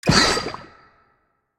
Sfx_creature_brinewing_flinch_01.ogg